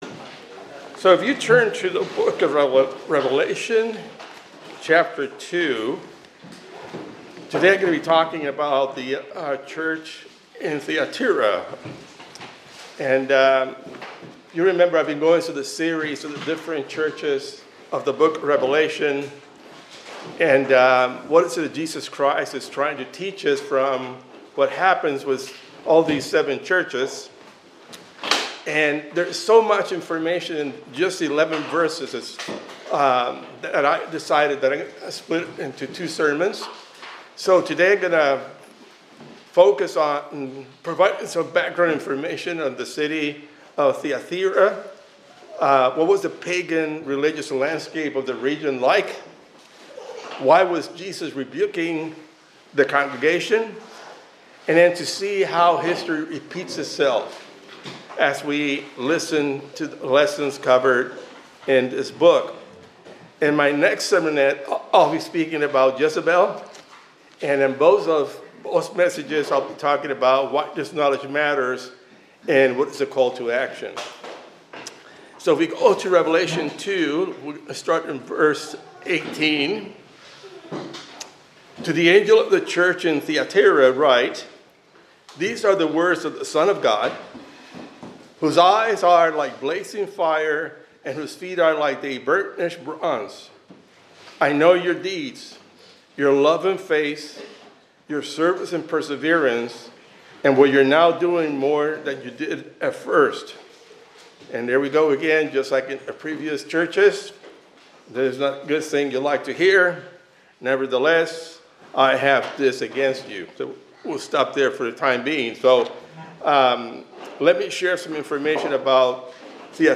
This sermon examines Jesus’ message to the church in Thyatira from Revelation 2, highlighting how Christians in this ancient city struggled to remain faithful amid strong pressures from influential trade guilds and a diverse, pagan religious environment. Drawing parallels to modern traditions, the message urges believers to hold firmly to biblical doctrine, avoid compromising with cultural or religious practices that conflict with their faith, and recognize Christ as the sole intercessor, emphasizing the importance of spiritual integrity in every generation.